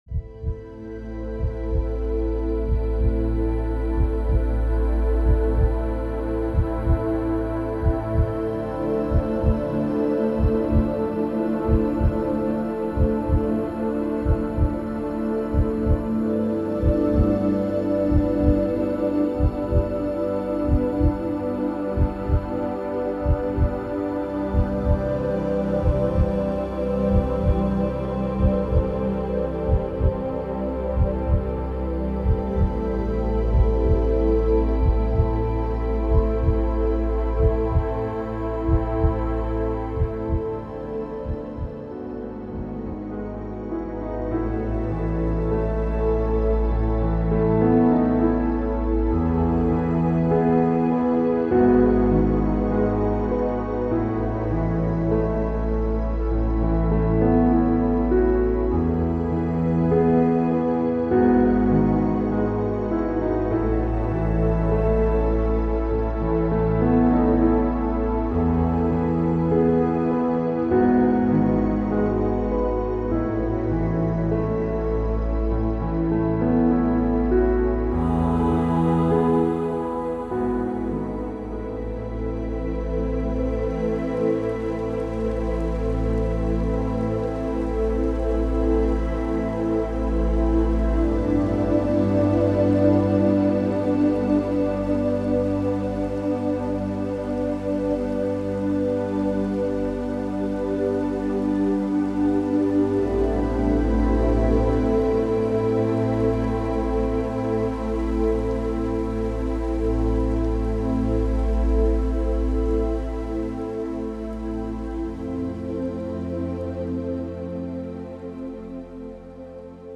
Lullaby – Herzschlagmusik
Entspannungsmusik